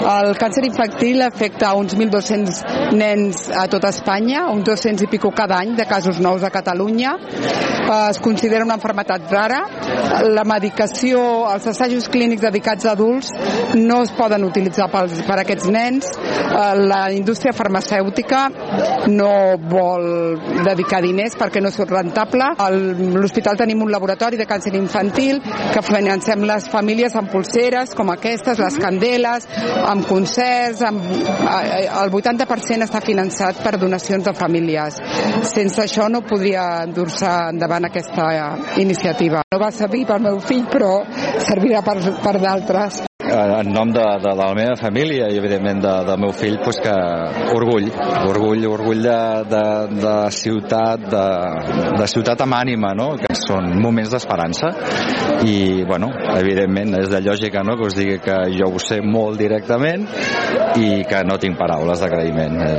Les dues famílies calellenques implicades en les iniciatives que s’estan duent a terme per sumar fons per a la investigació, entre elles també la venda de les polseres Candela, van posar de relleu el calat del suport que s’ha rebut de la ciutat i també la important feina de divulgació que s’està fent a redós de la MEGAPANERA.